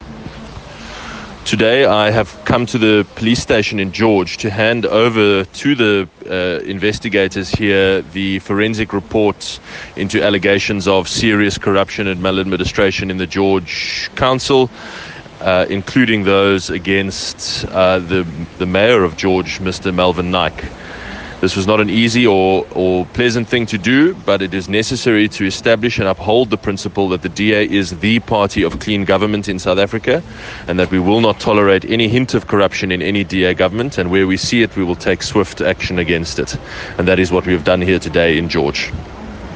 here for a soundbite by Geordin Hill-Lewis MP.